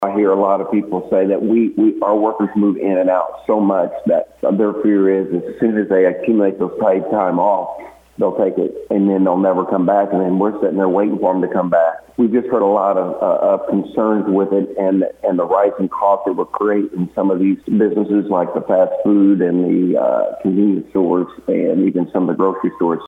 However, State Senator for Missouri's 3rd District, Mike Henderson, says there is an ongoing discussion of changes to the paid time off portion of the approved Proposition.